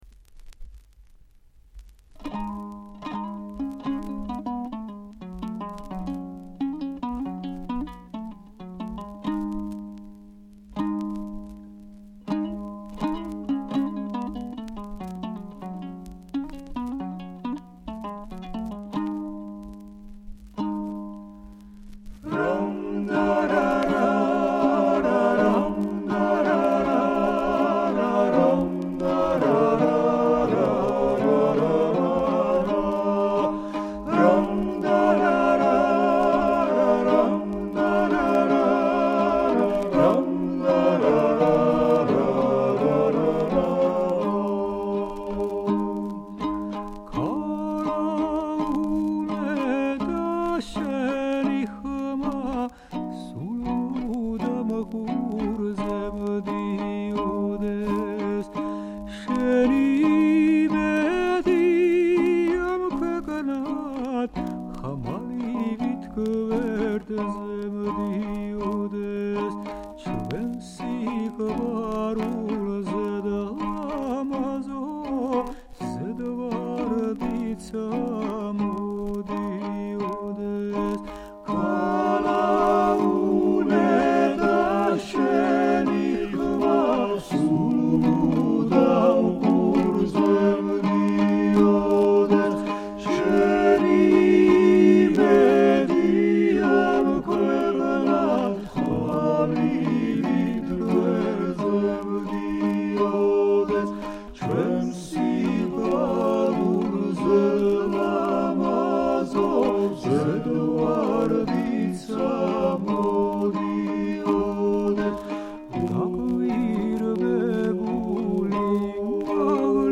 Folk ensemble
Keywords: ქართული ხალხური სიმღერა